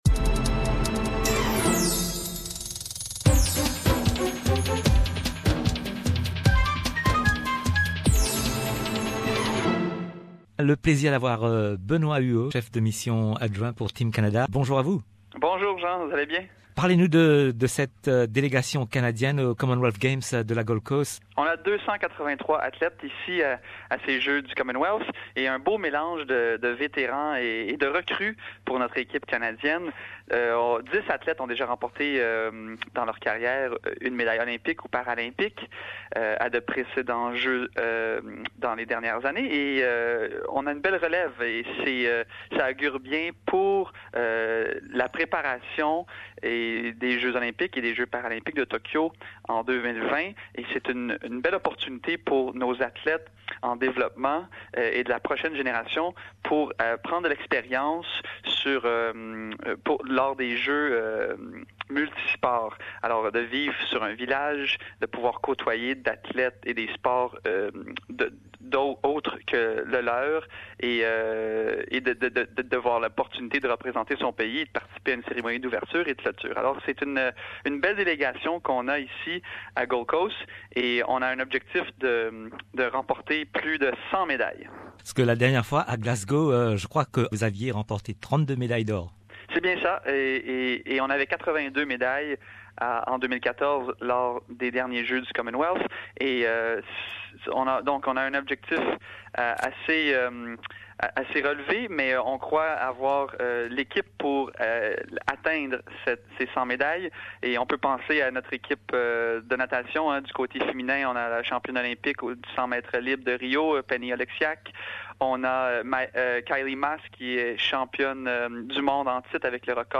Benoît Huot, Chef de mission adjoint de Team Canada nous parle des objectifs et des attentes pour les Jeux du Commonwealth qui se déroulent sur la Gold Coast au Queensland du 4 au 15 avril.